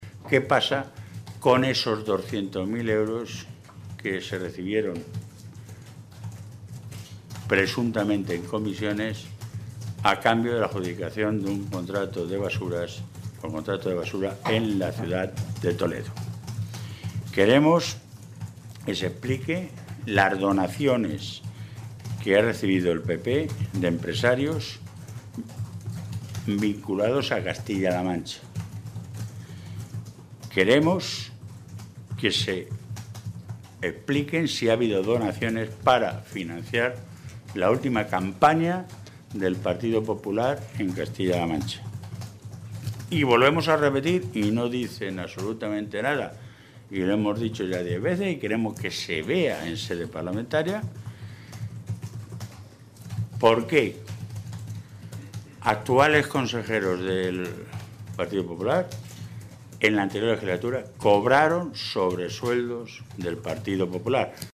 Vaquero hacía este anuncio esta mañana, en Toledo, en una comparecencia ante los medios de comunicación en la que advertía que sería “un error”, que la mayoría del PP en la Mesa de la Cámara se negara a calificar y tramitar la propuesta socialista.
Cortes de audio de la rueda de prensa